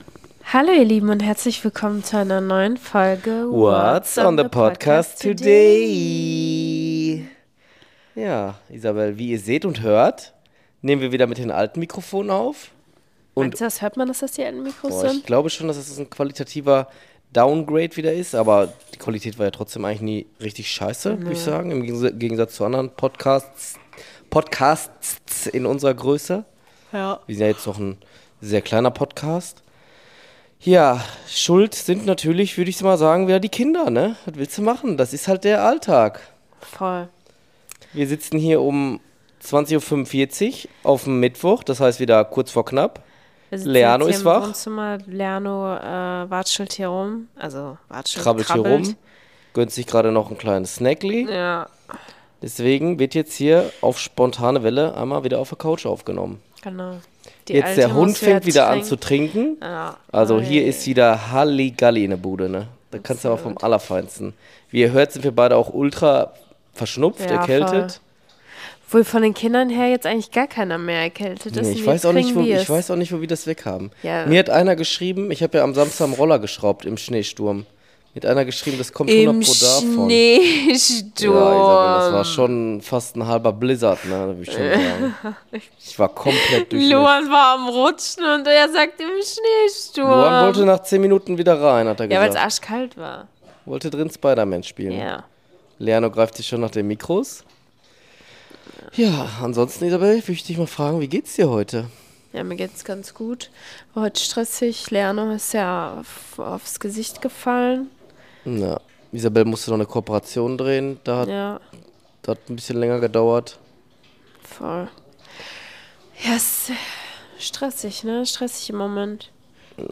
Zwischen Kindergebrabbel und Hundegeschnaufe